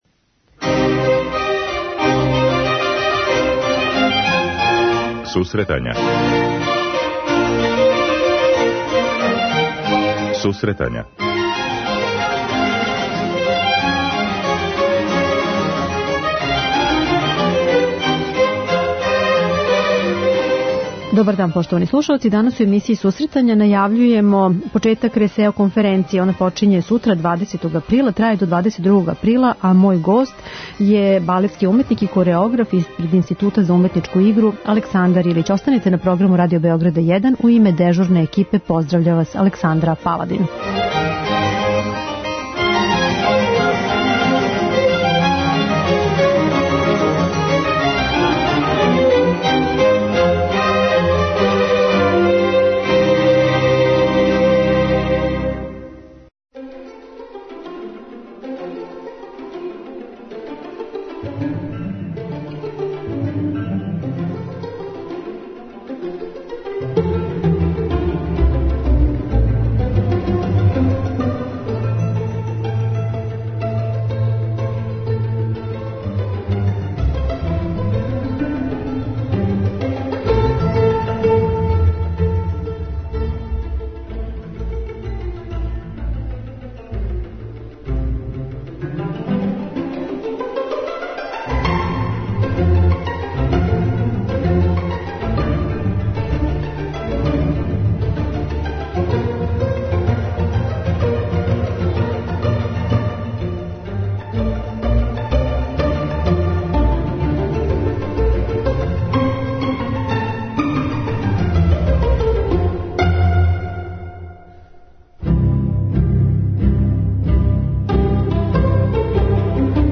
Сутра у Београду почиње RESEO пролећна конференција, чији је домаћин Институт за уметничку игру. О програму конференције данас разговарамо